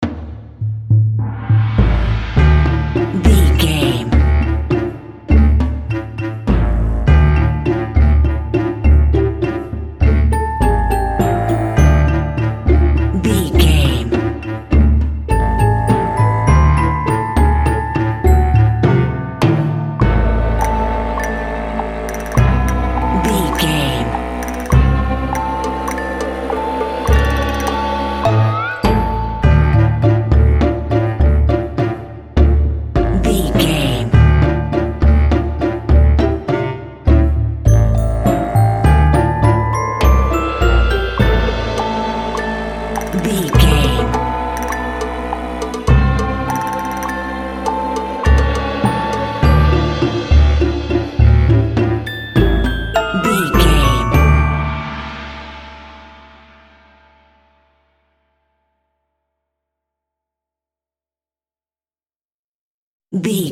Uplifting
Dorian
percussion
flutes
piano
orchestra
double bass
goofy
comical
cheerful
perky
Light hearted
quirky